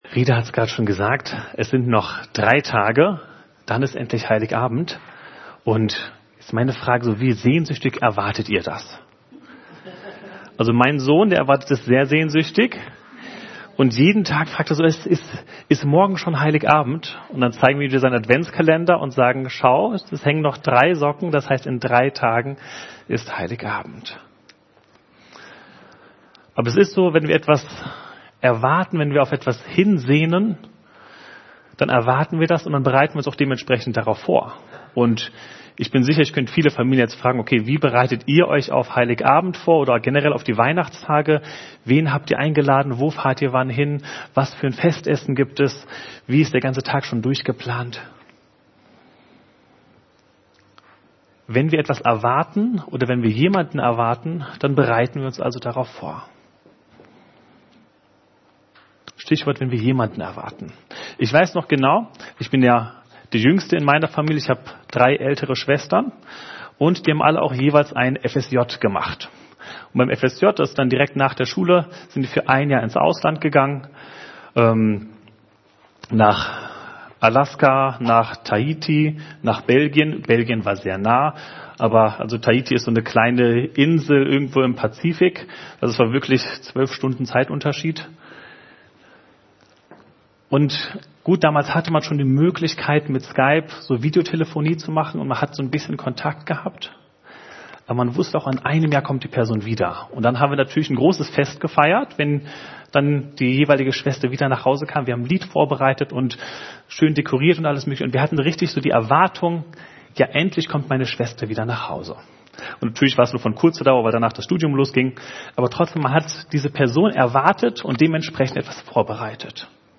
Predigt